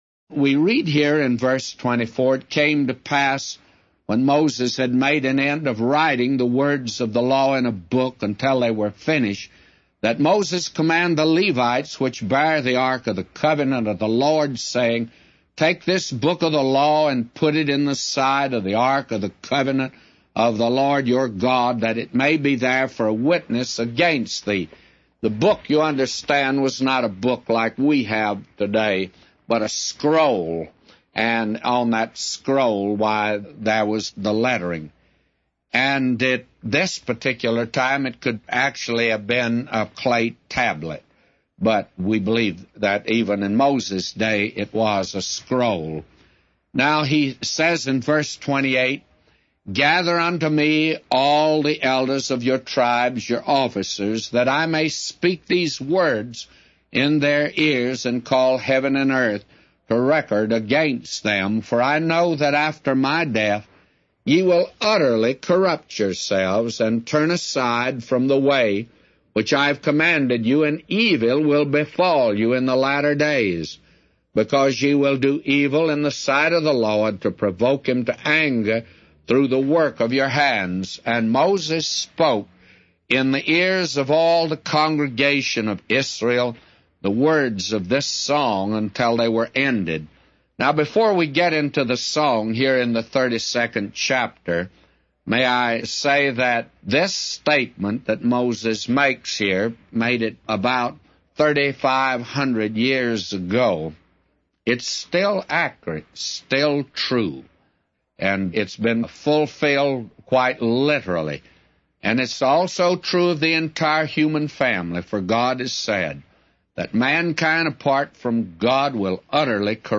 A Commentary By J Vernon MCgee For Deuteronomy 31:14-999